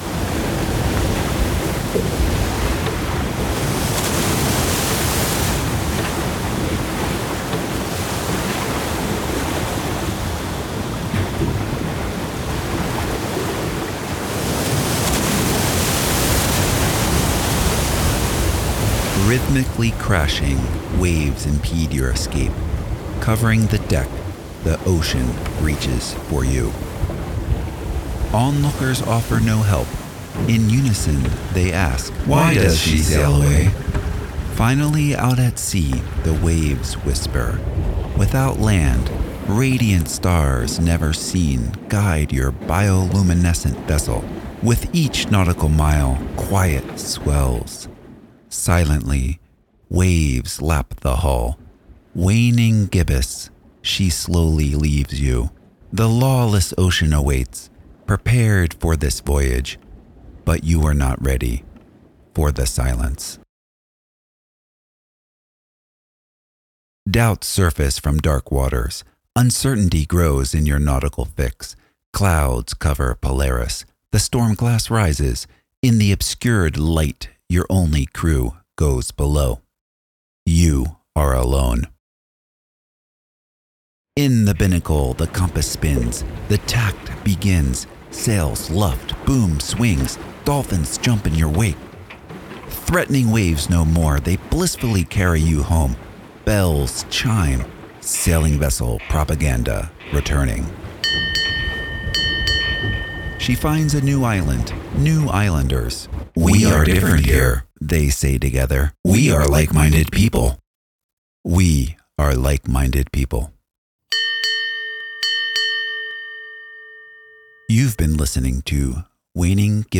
I performed a reading of my poem inspired by it: